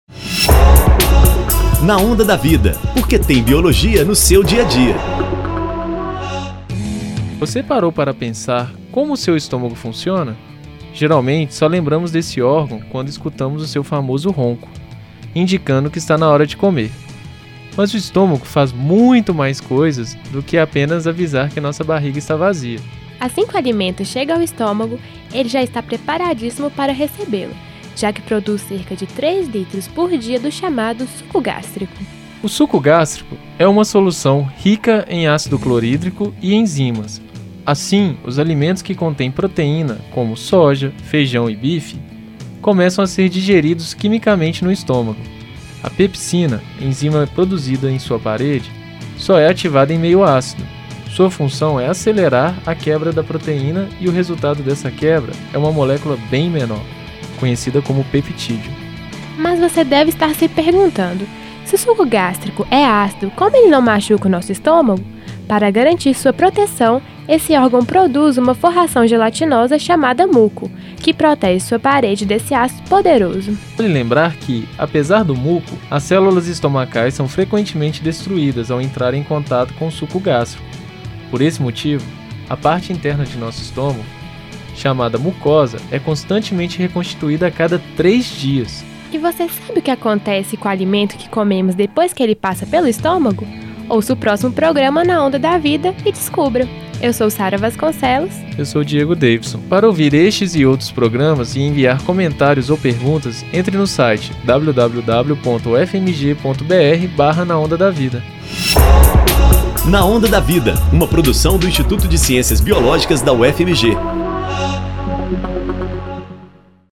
Na Onda da Vida” é um programa de divulgação científica através do rádio